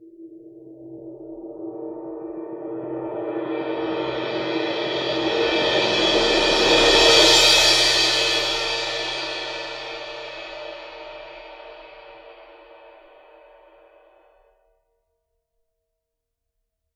susCymb1-cresc-Long_v1.wav